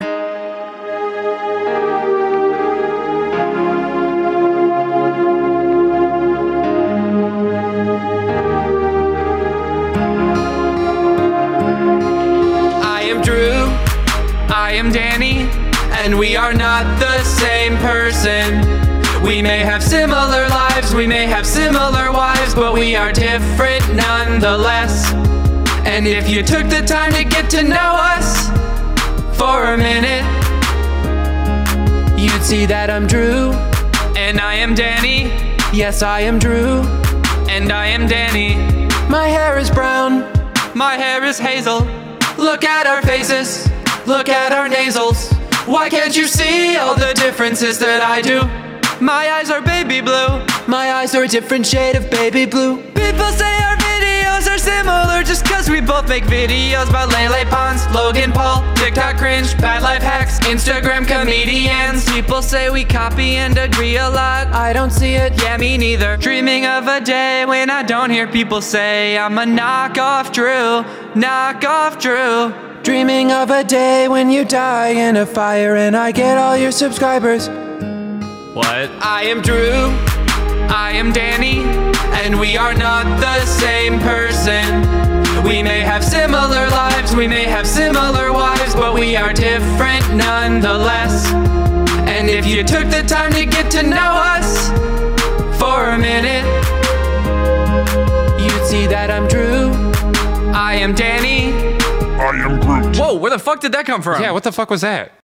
BPM145
MP3 QualityMusic Cut